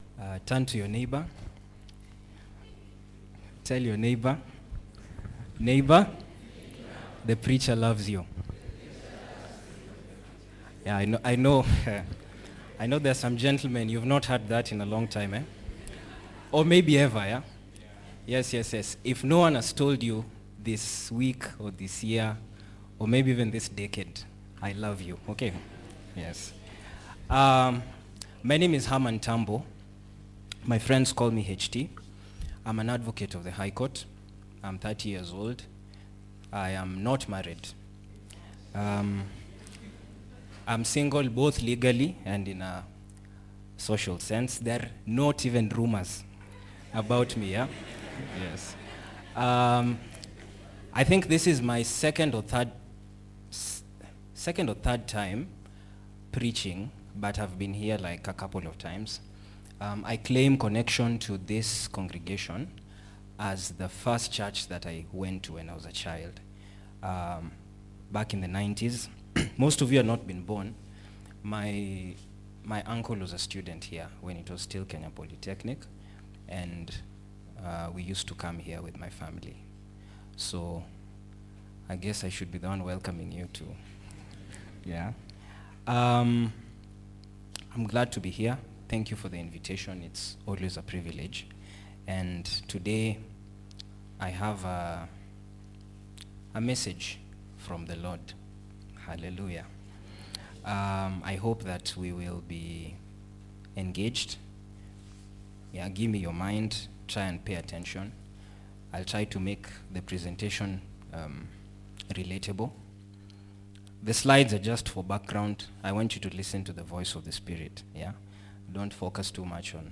Tuksda Church -Sermons